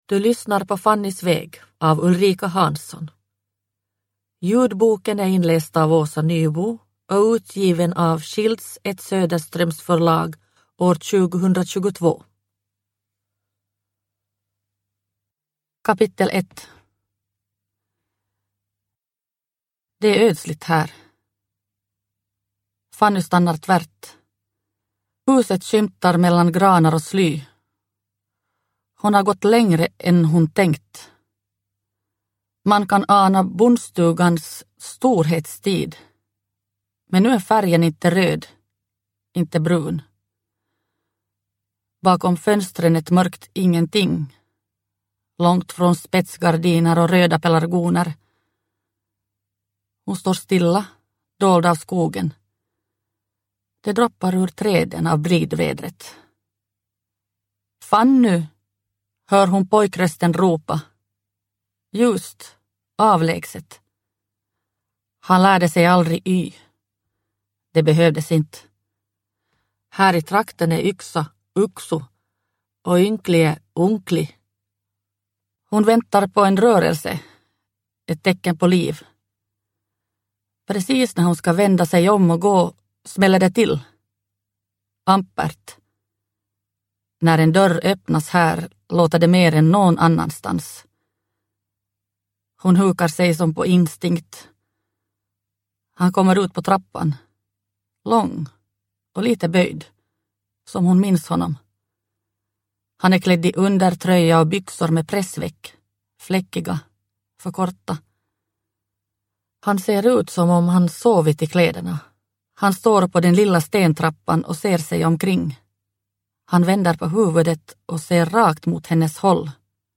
Fannys väg – Ljudbok – Laddas ner